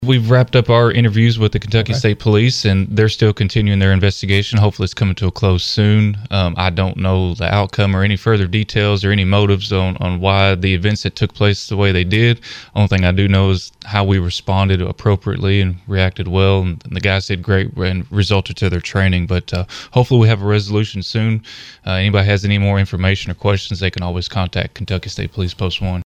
click to download audioSheriff Acree says the investigation into the shooting is continuing by Kentucky State Police.